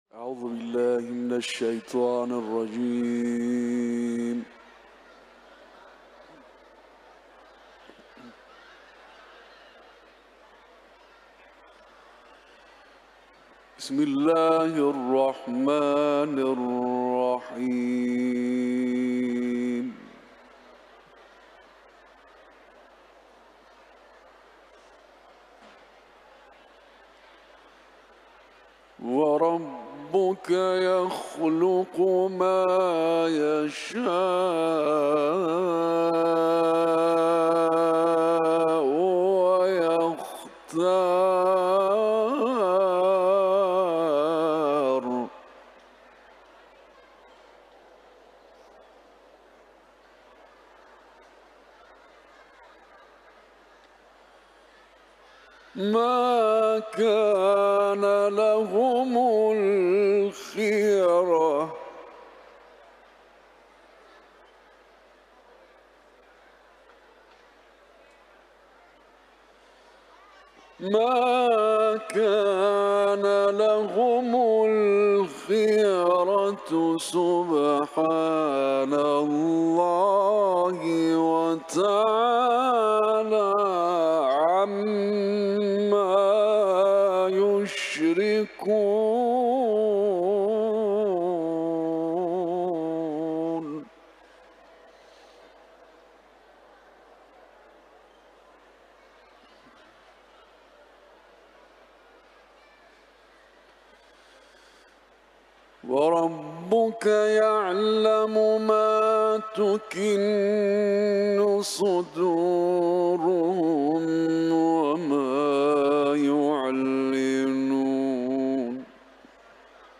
که در حرم مطهر رضوی به اجرا رسیده است
سوره قصص ، تلاوت قرآن